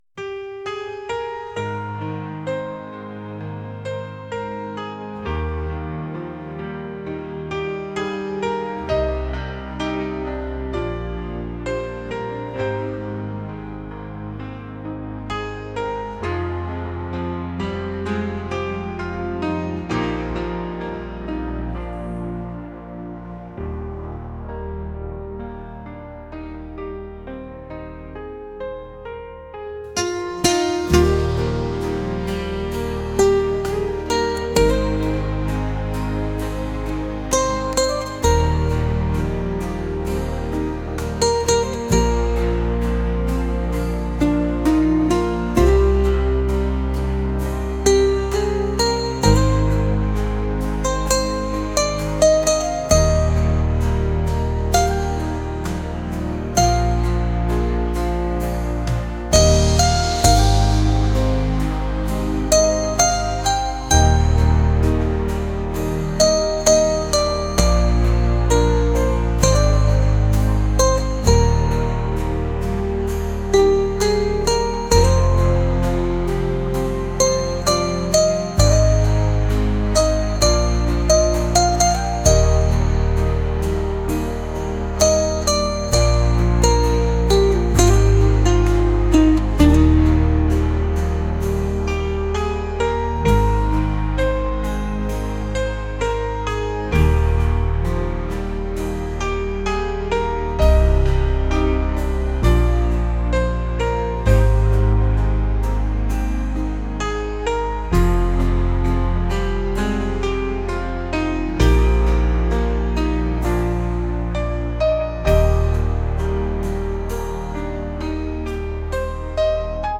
pop | soul & rnb | acoustic